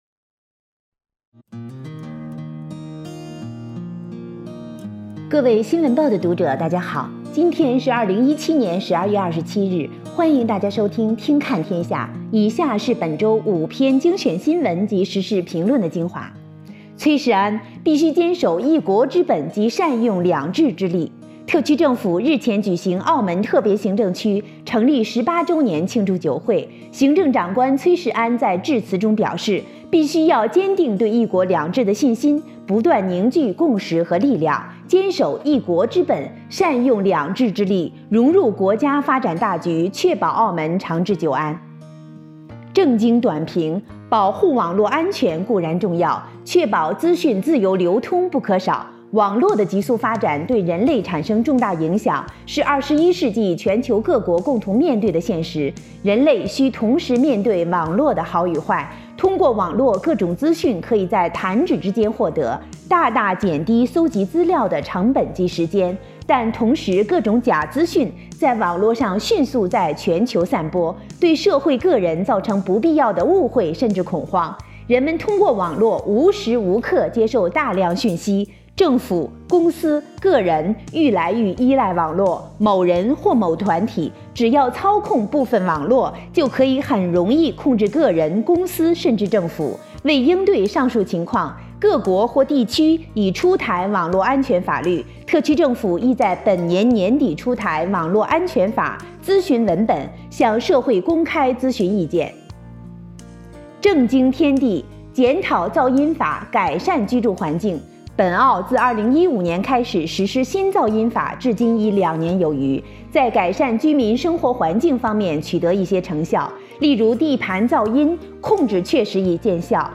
[語音播報]新聞及時事評論精華（普通話）